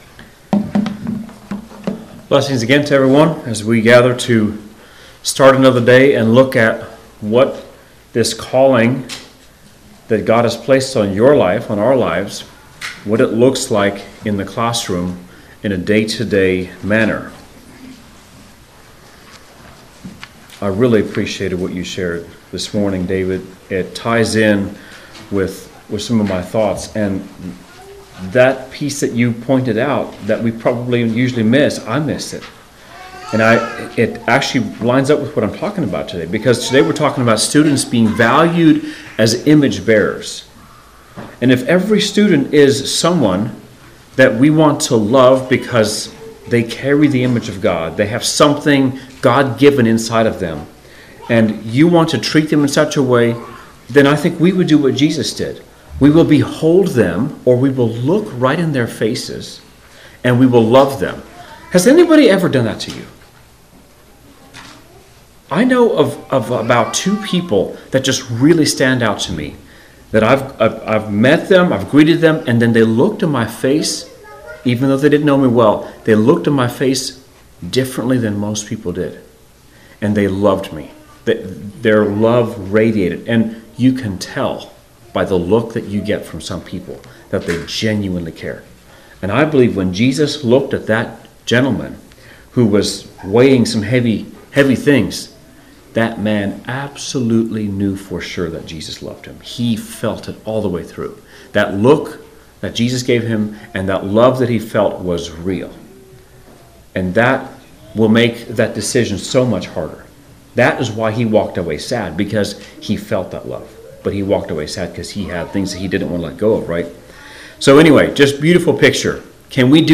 Midwest Teachers Week 2025 Recordings
keynote sessions